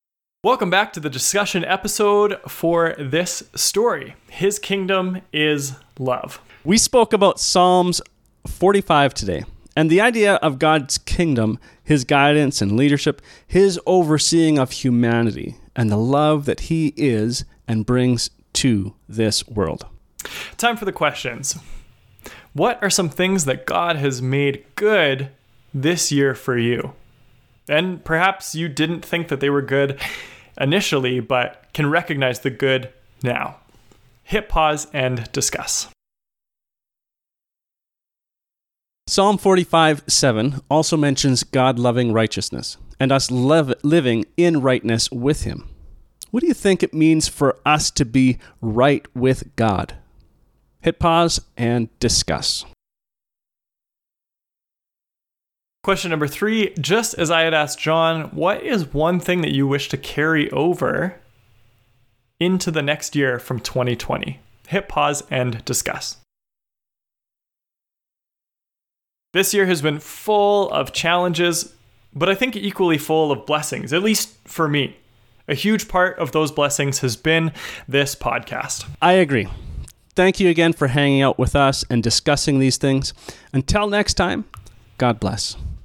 Discussion | His Kingdom is Love